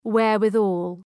Προφορά
wherewithal.mp3